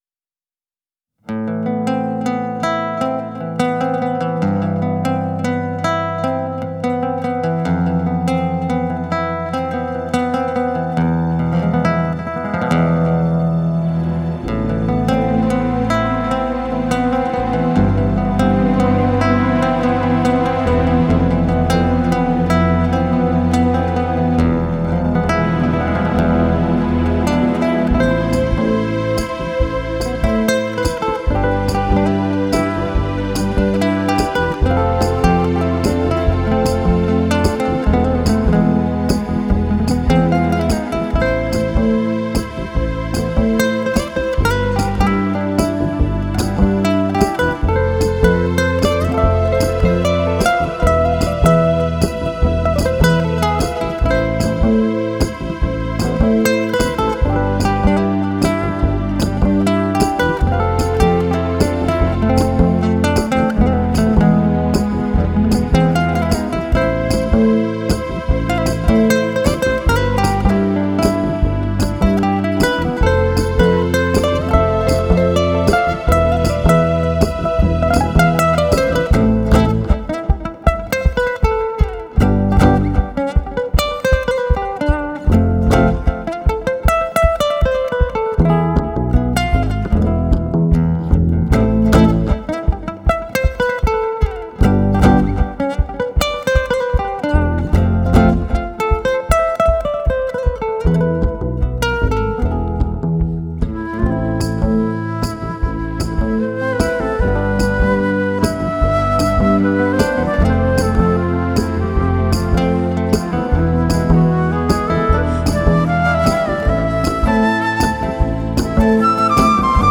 live instrumental album
GK Guitar
Harmonicas
Flute
Percussion